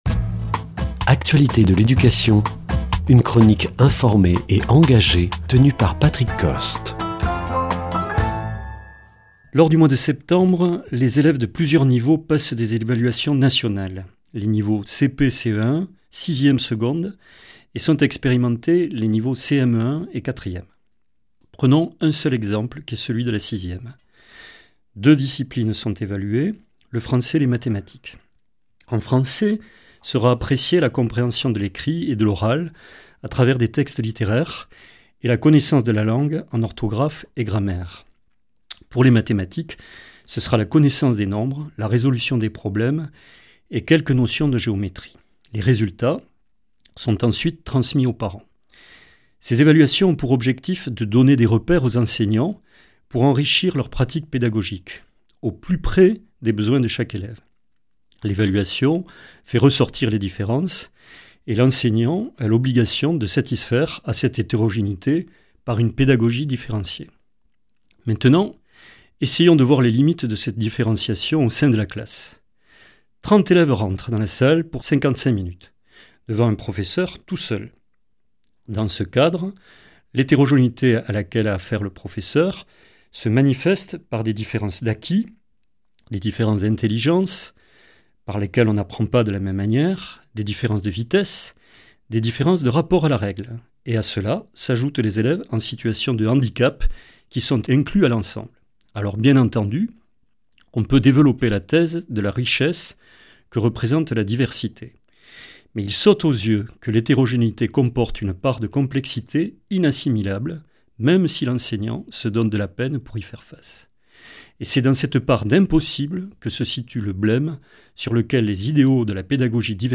[ Rediffusion ] Les évaluations nationales prévues sur six niveaux en septembre sont-elles un bon moyen pour faire réussir les élèves ?
Chroniqueur